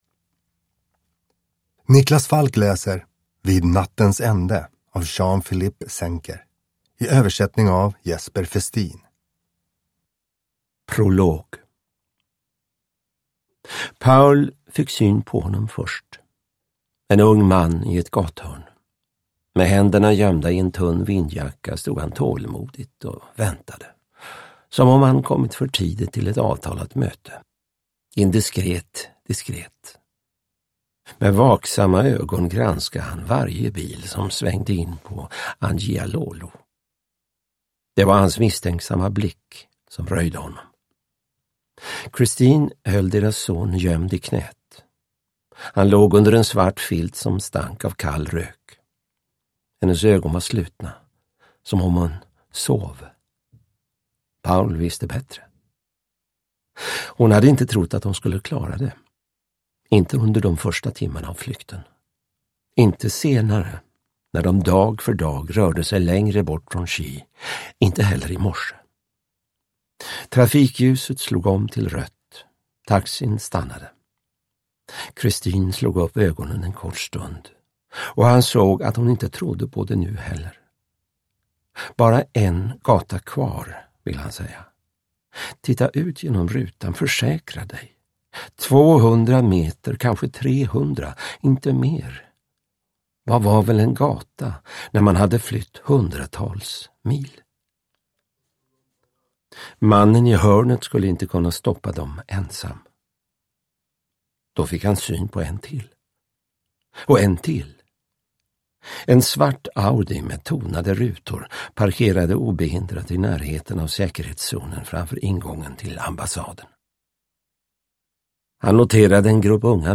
Vid nattens ände – Ljudbok – Laddas ner
Uppläsare: Niklas Falk